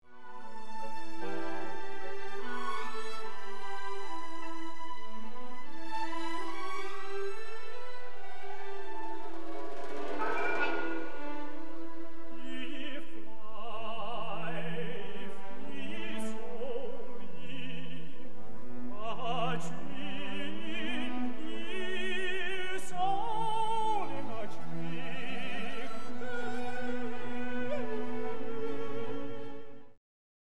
オペラ